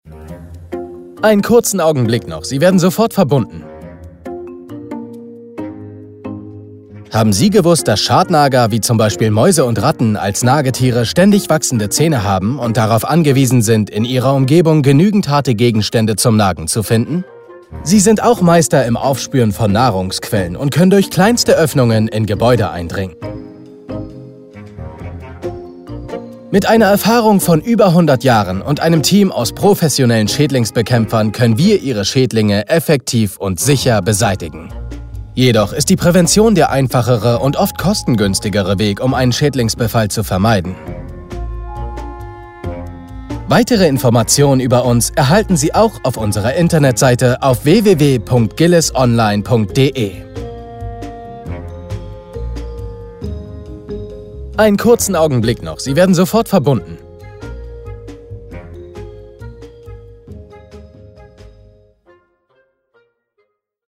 Telefonansage junge Stimme
Seine Stimme klingt jung, cool, frisch und locker.